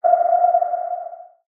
submarine ping.ogg